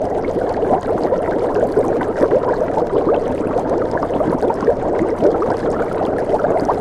cauldron.ogg.mp3